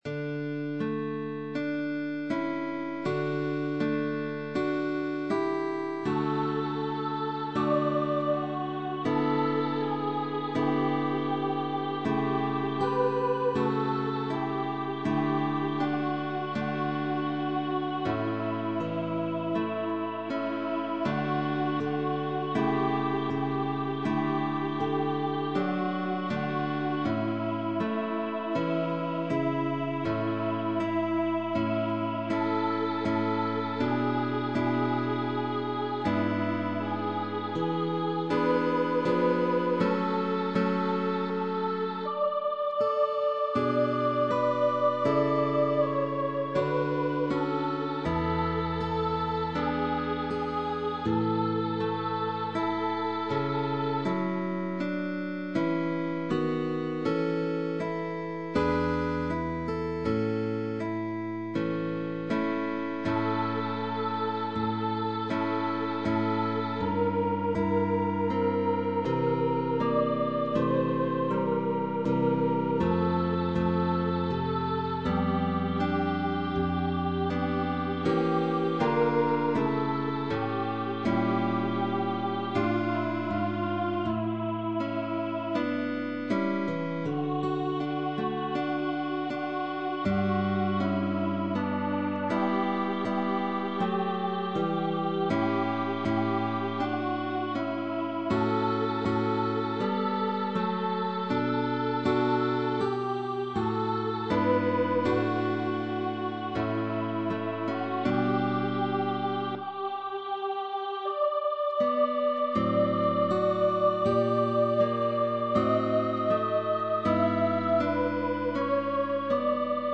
VOZ (Soprano) y GUITARRA
pequeño motete musical
Voz y Guitarra